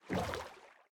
paddle_water1.ogg